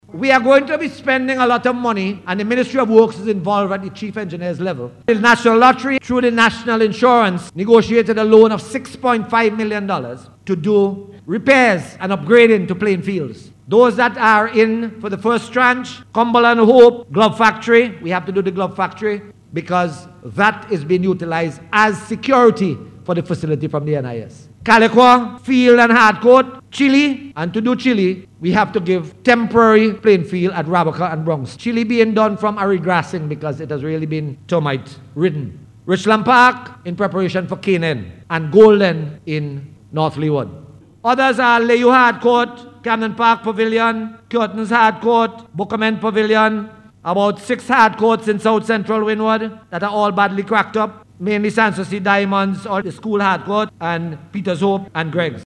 Minister of State in the Prime Minister’s Office, Senator Julian Francis outlined the various Sports Facilities that will be taken care of during the 2015 National Budget Debate in Parliament yesterday…